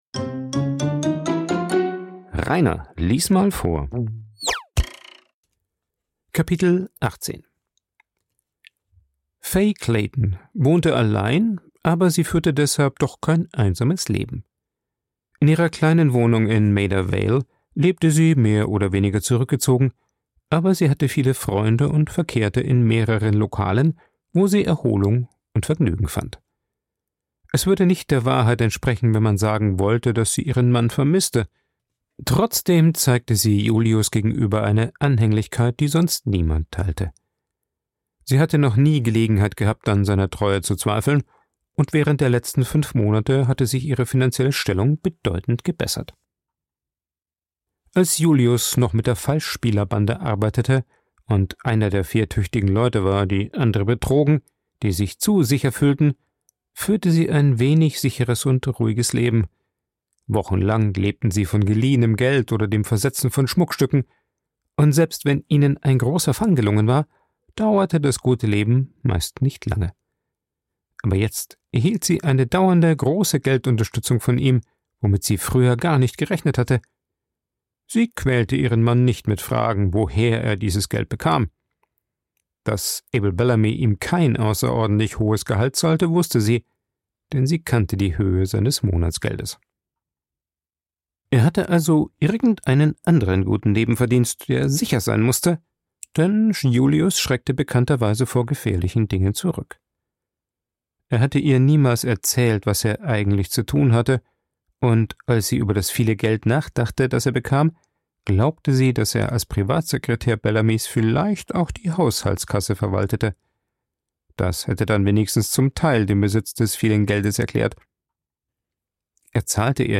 Ein Vorlese Podcast
Coworking Space Rayaworx, Santanyí, Mallorca.